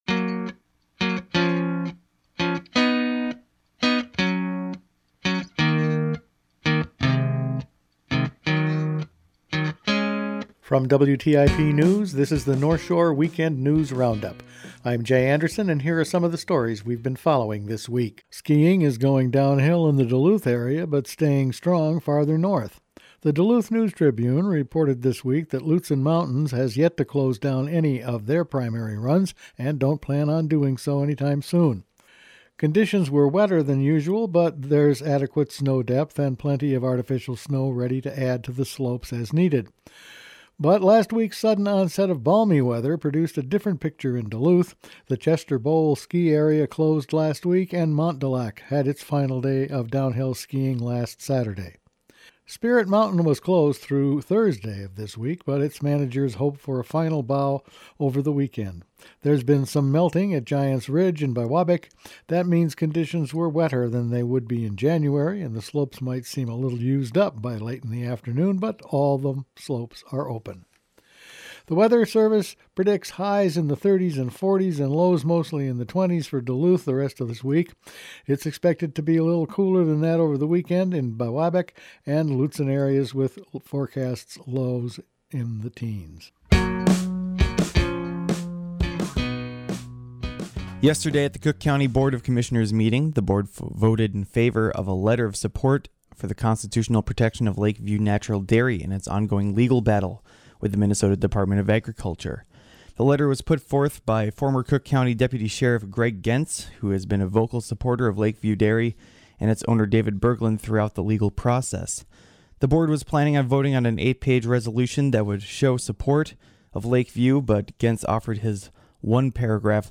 WTIP Weekend News Roundup for March 21